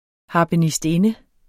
Udtale [ ˌhɑːbənisdˈenə ]